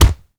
punch_general_body_impact_06.wav